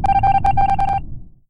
among us death.ogg